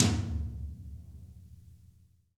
TOM MIGRN00L.wav